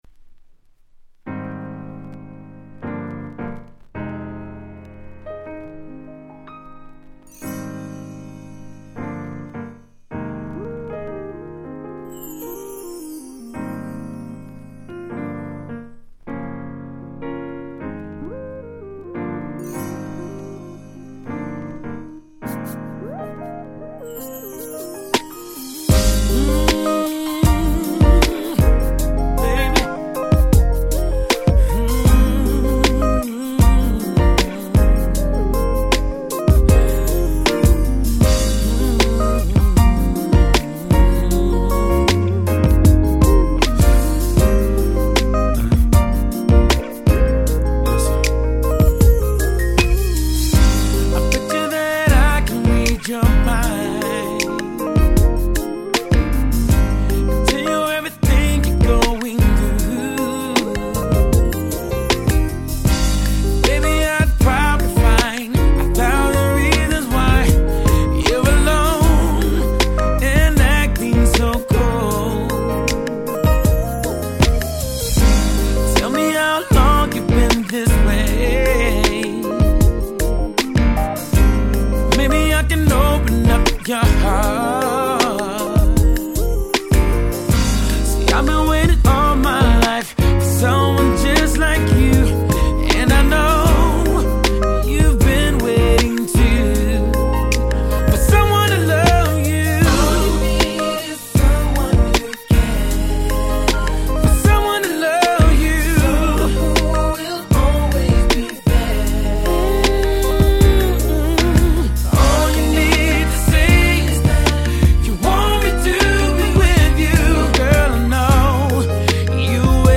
01' Big Hit Slow Jam !!
珠玉の激甘Slow。